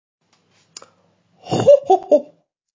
Hohoho. Efeito Sonoro: Soundboard Botão
Hohoho. Botão de Som